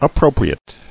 [ap·pro·pri·ate]